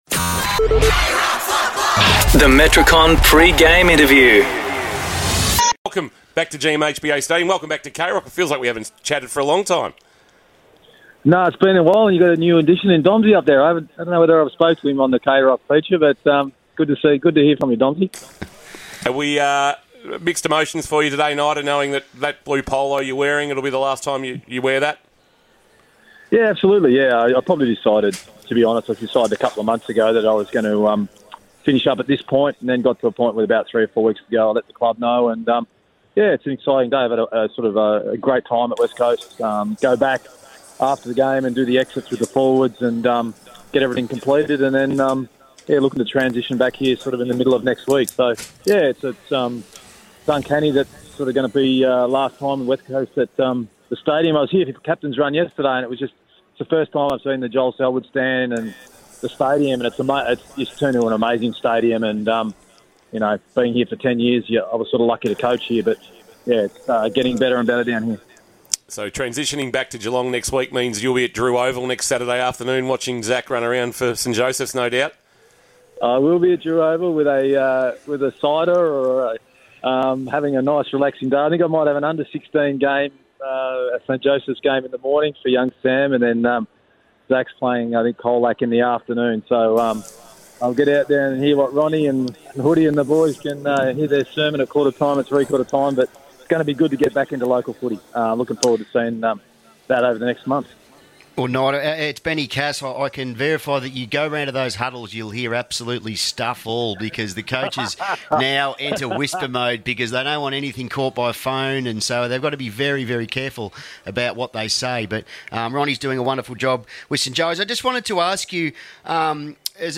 2024 - AFL - Round 24 - Geelong vs. West Coast - Pre-match interview: Matthew Knights (West Coast assistant coach)